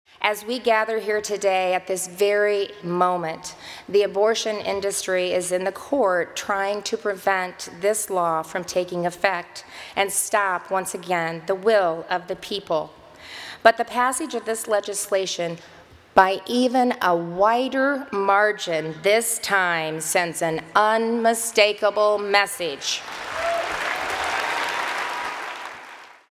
Reynolds signed the bill on stage at The Family Leader’s summit in Des Moines. The crowd of two-thousand evangelical Christians at the event stood as she put her signature on the document and cheered her along with the Republican lawmakers who joined her on stage.